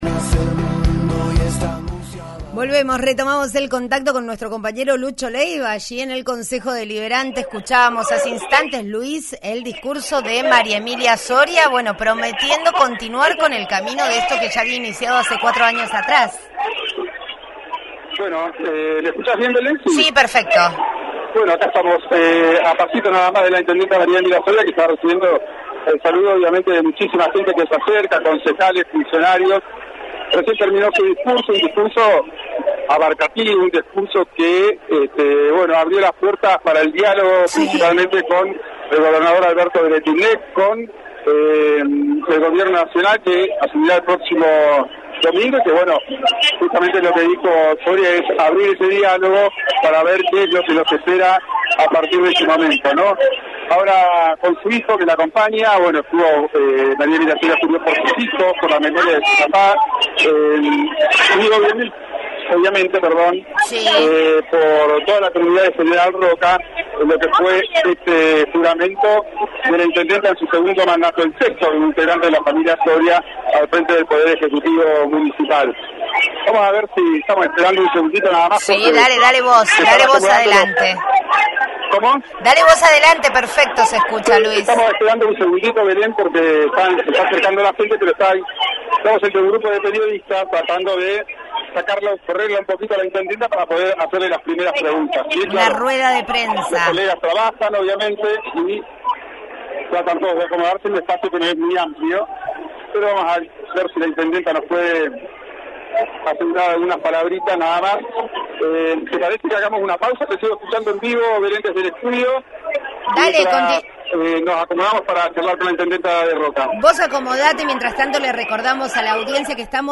Un discurso breve, cauto y conciliador marcó esta mañana la segunda jura de María Emilia Soria como intendenta de Roca.
Poco más de seis minutos duró el mensaje de la jefa comunal, reelecta el 12 de marzo pasado y protagonista hoy del acto realizado en el Concejo Deliberante, donde también juraron los ocho ediles y los integrantes del Tribunal de Cuentas, además de los seis secretarios del Poder Ejecutivo.